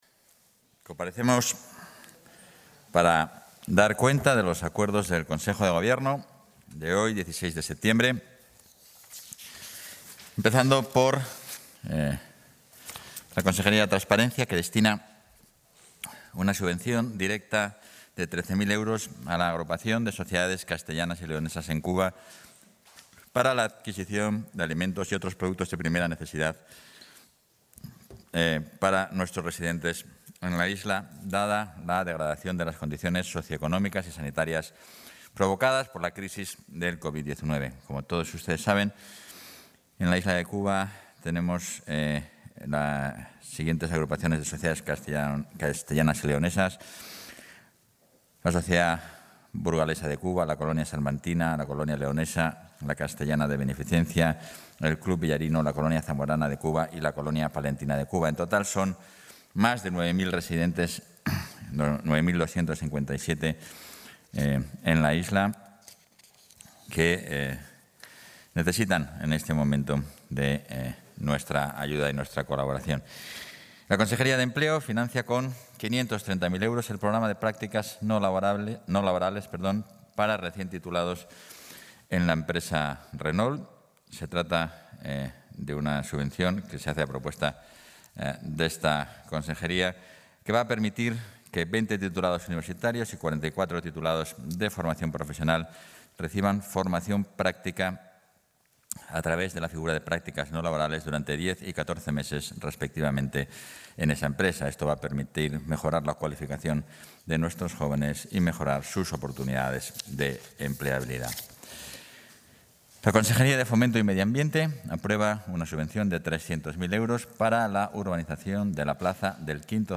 Intervención del vicepresidente y portavoz.